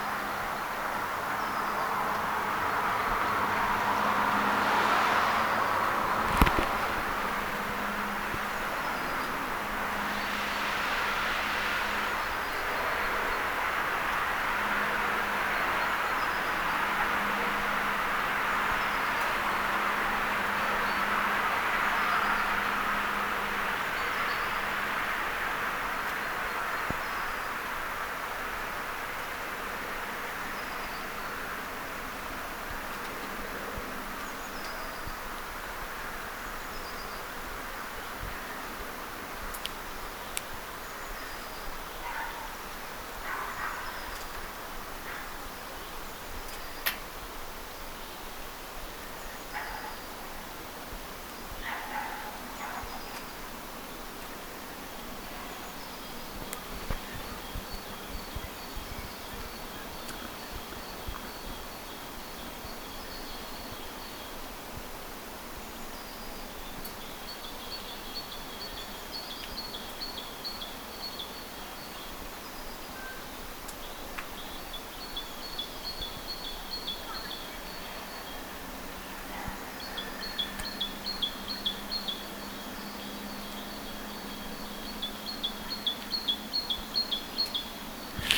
sinitiainen laulaa sitä rautiaismatkintalaulua
sinitiainen_laulaa_sita_rautiaisen_lentoaantelymatkintalauluaan.mp3